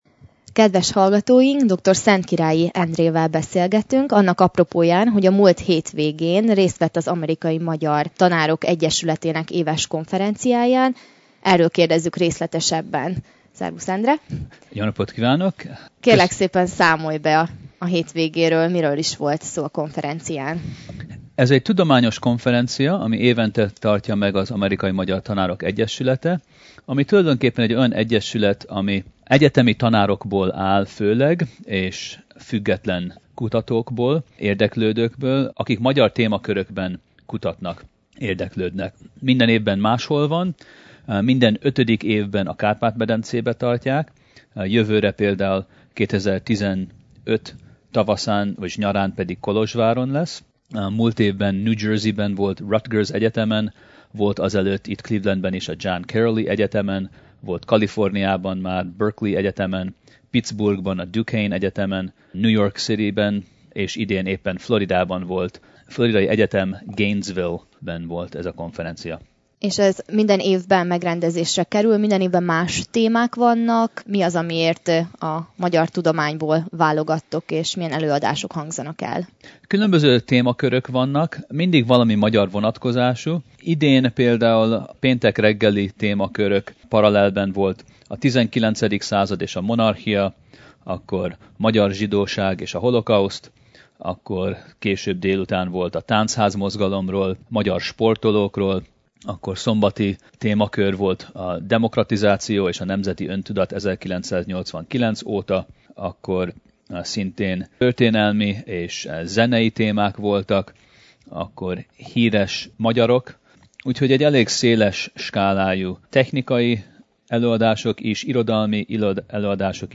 Hallgassuk meg a vele készült interjút